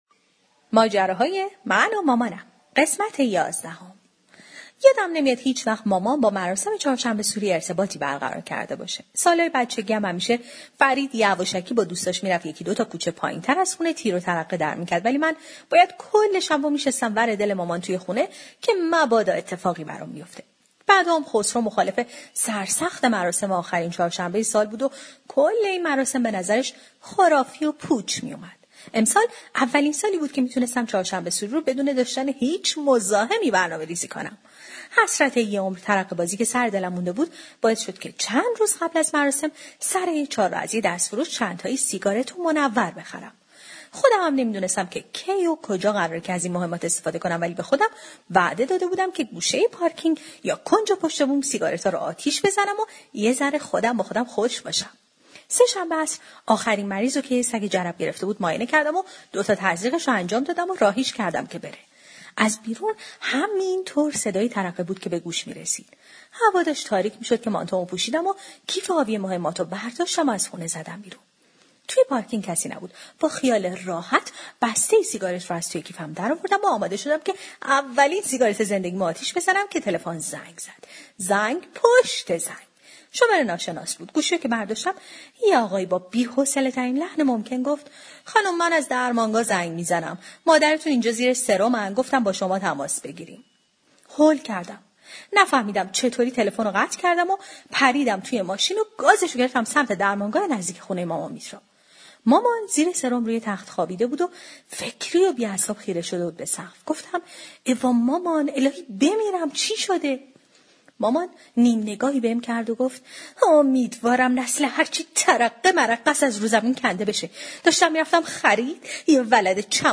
طنزصوتی/ ماجراهای من و مامانم ۱۱